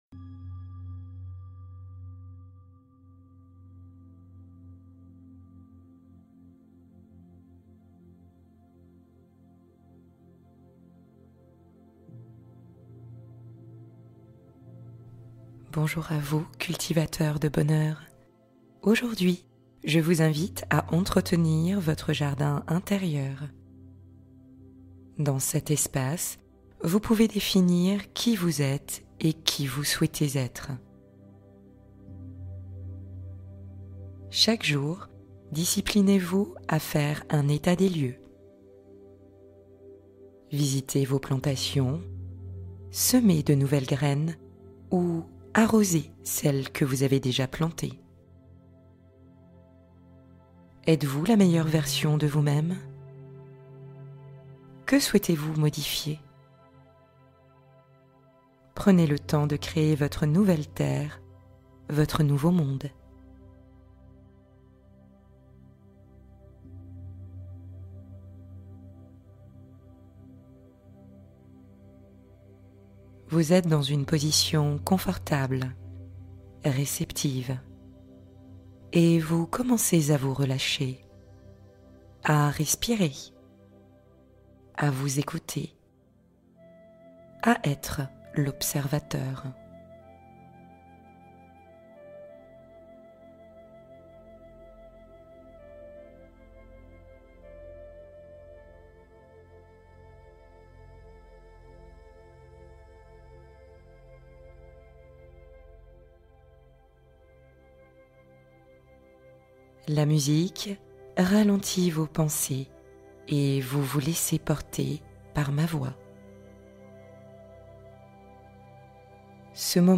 Chemin de lumière : traversée guidée vers la clarté intérieure